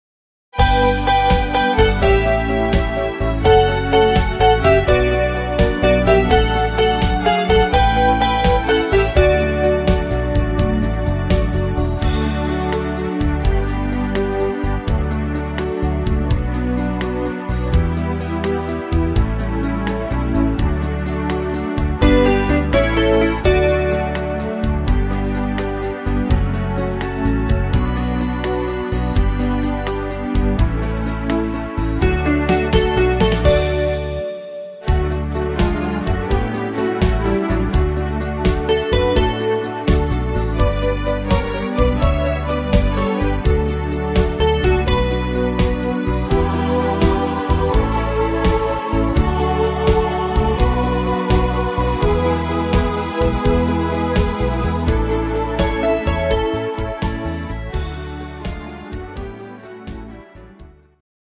Rhythmus  Slowrock
Art  Deutsch, Volkstümlicher Schlager